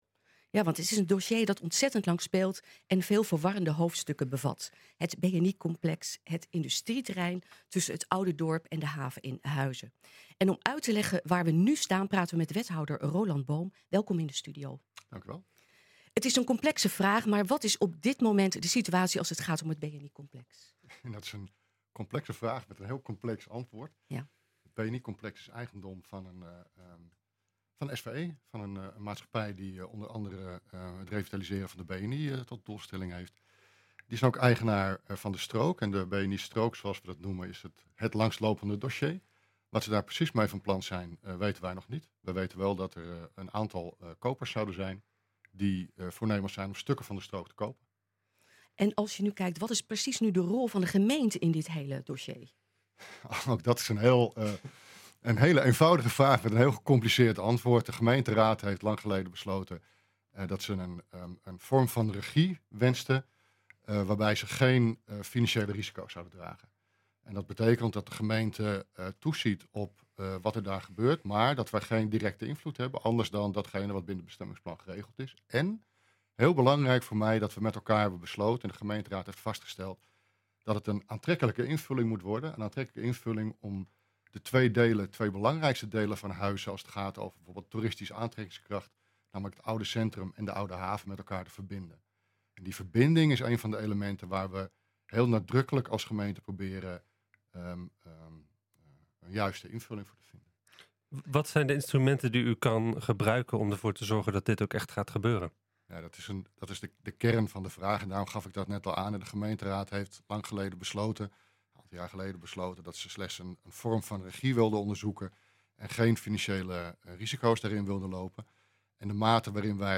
Het is een dossier dat ontzettend lang speelt en veel verwarrende hoofdstukken bevat: het BNI-complex op het industrieterrein tussen het oude dorp en de haven in Huizen. Om uit te leggen waar we nu staan, praten we met wethouder Roland Boom.